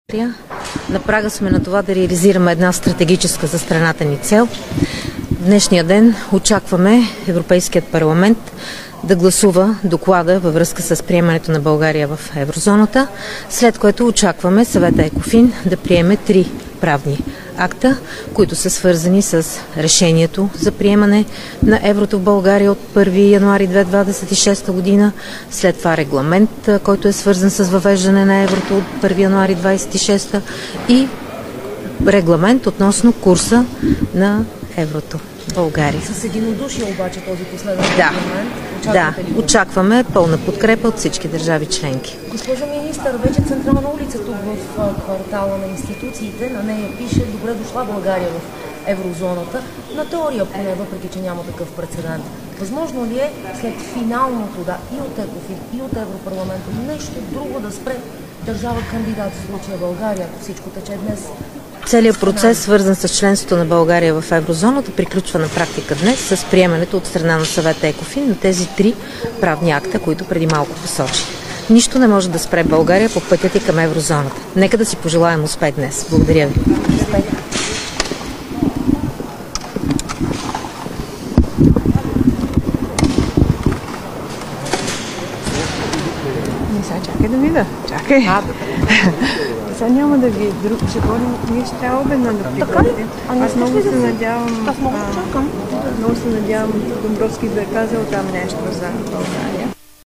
9.55 - Брифинг на министъра на финансите Теменужка Петкова.
Директно от мястото на събитието